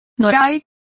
Complete with pronunciation of the translation of bollard.